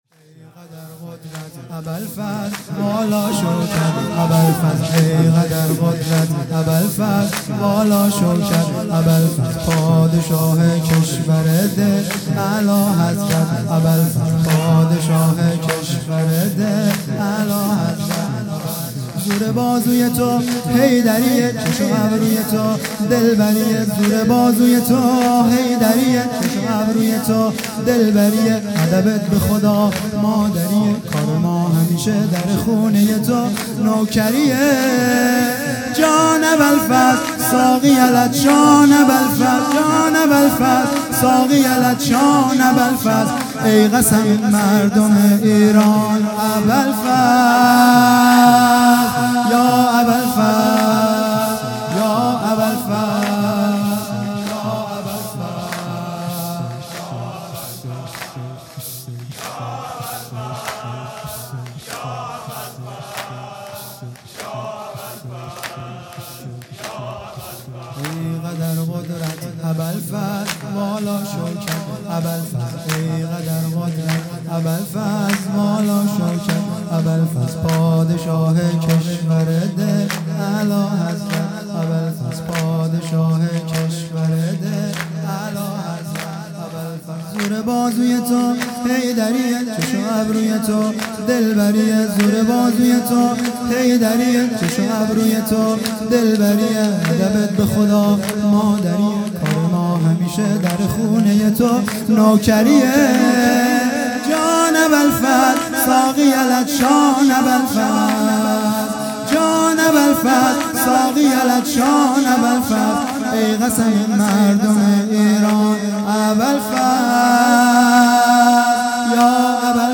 ای قدر قدرت ابالفضل|جلسه هفتگی